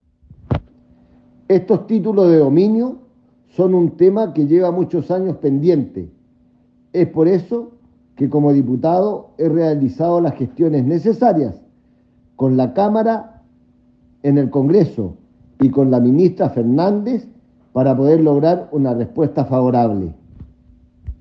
El parlamentario, ademas agregó que, estos títulos de dominio son un tema que lleva muchos años pendientes: